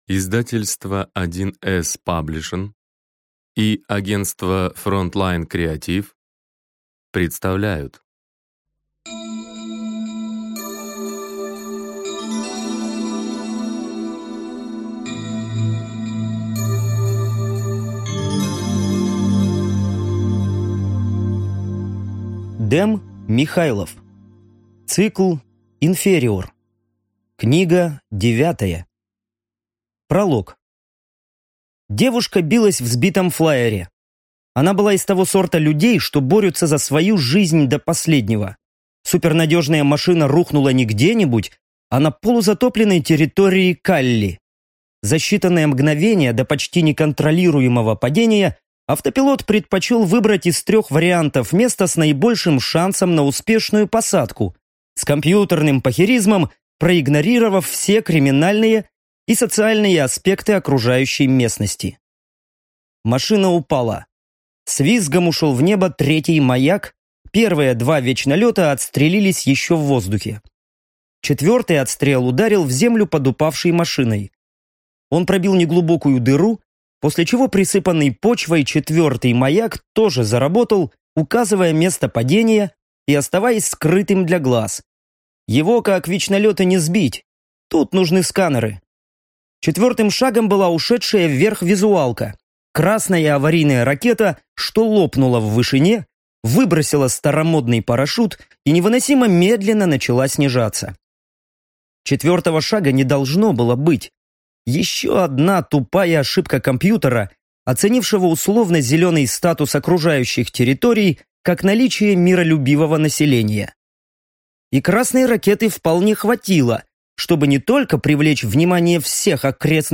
Аудиокнига Инфер. Книга 9 | Библиотека аудиокниг